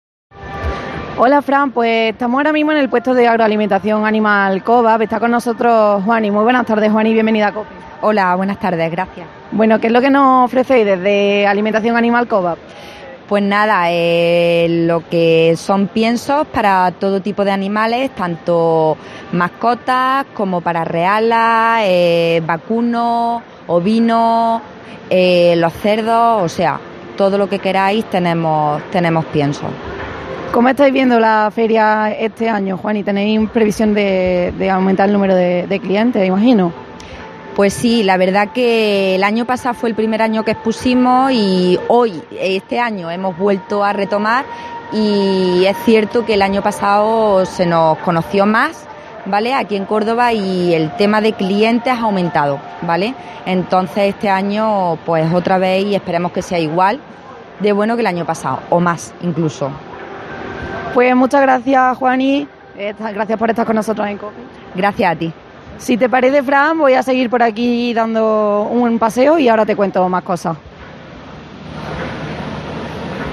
Por el set de radio de COPE hoy han pasado distintos protagonistas para contarnos cómo se organiza, quiénes lo forman, cómo están los distintos sectores y qué actividades se han programado para esta nueva edición de Intercaza 2019, la Feria Cinegética, de Turismo, Ocio Activo y Medio Ambiente.